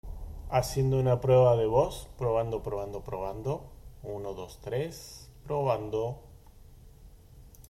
Realizamos una grabación de nuestra voz, que se captura bastante bien, muy clara, gracias a disponer de micrófonos duales (estéreo) con solución Realtek. La CAM graba audio en 10M, S/N>48dB y se aprecia en este ejemplo.
Streamplify-CAM-Review-Gragacion-de-Mic.mp3